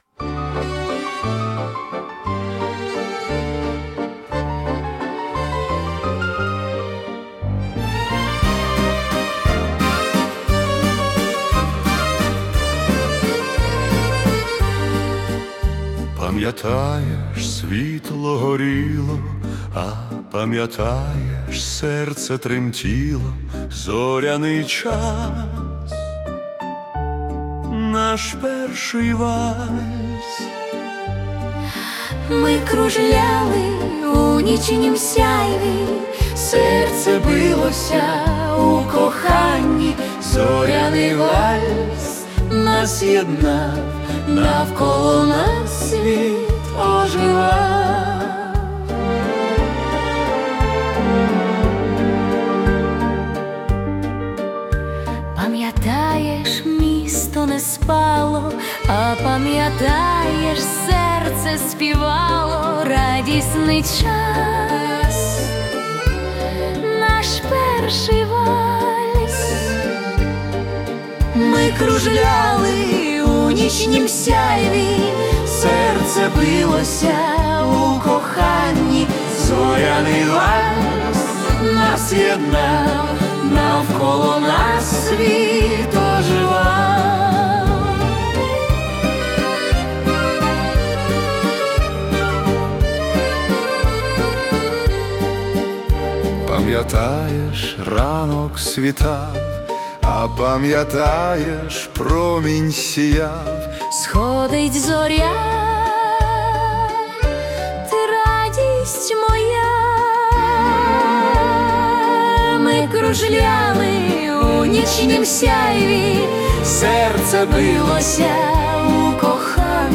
🎵 Жанр: Viennese Waltz / Classical